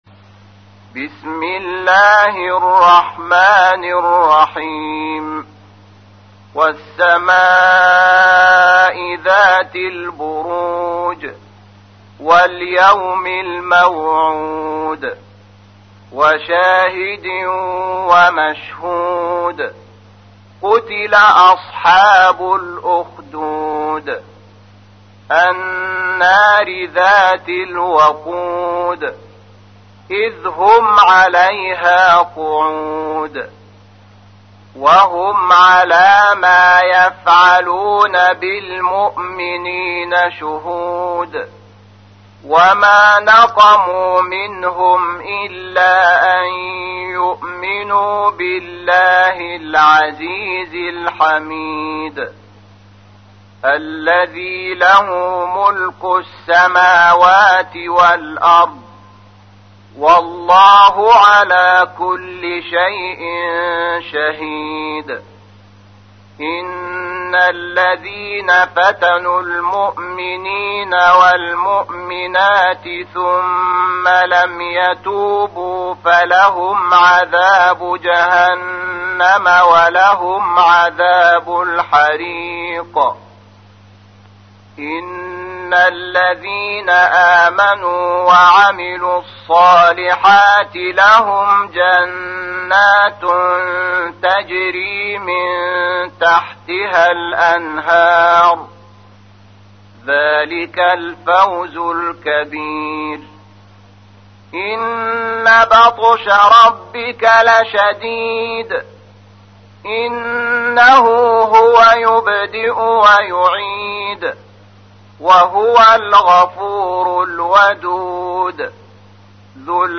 تحميل : 85. سورة البروج / القارئ شحات محمد انور / القرآن الكريم / موقع يا حسين